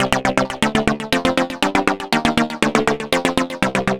Sprise Leads.wav